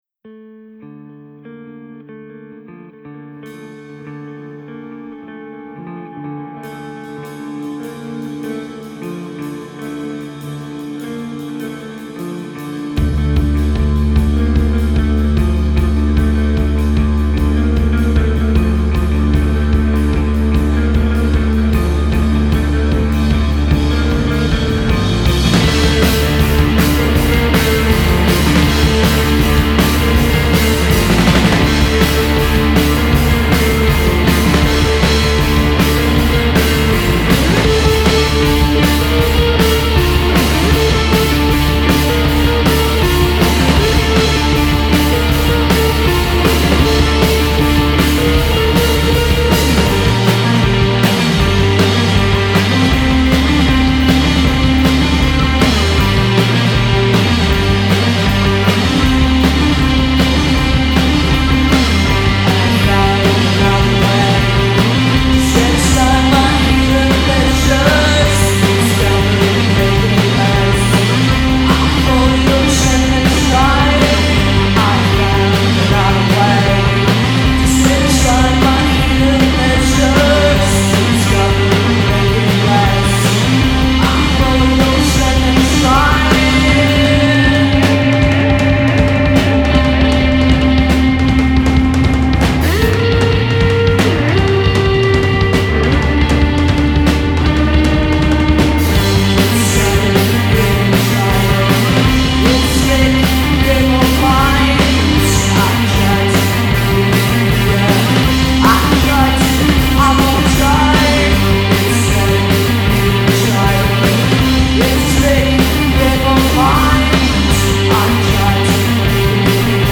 art-punk band
reverb-soaked atmosphere
the drive and immediacy is palpable and dramatic.
bass
drums